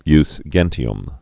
(ys gĕntē-əm, jŭs jĕnshē-əm)